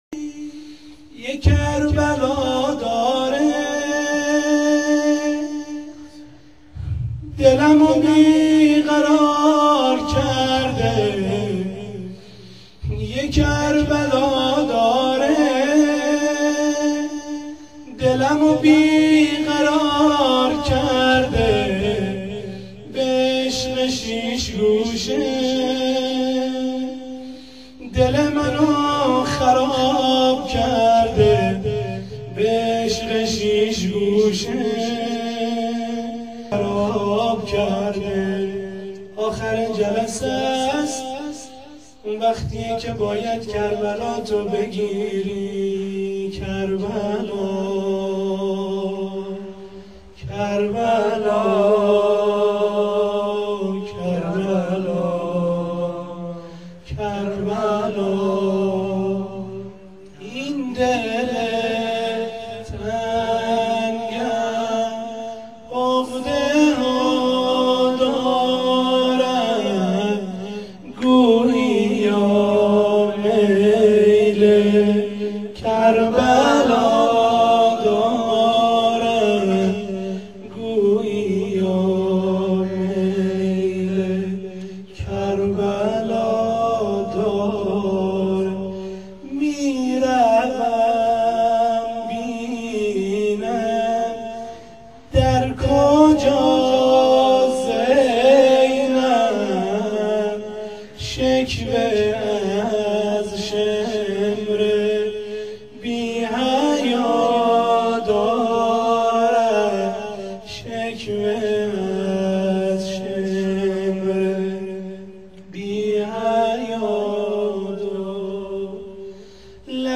روضه-پایانی.wma